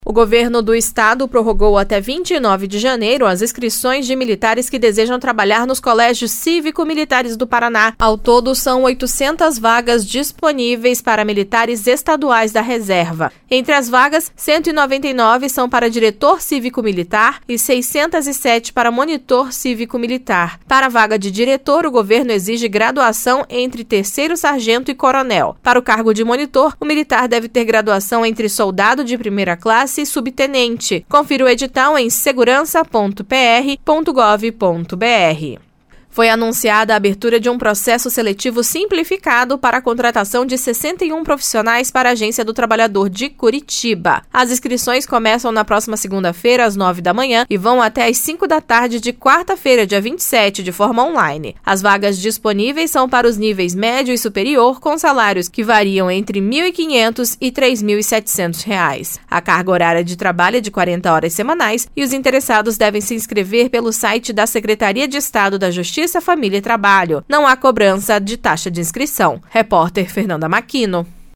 Mais notícias no boletim de serviços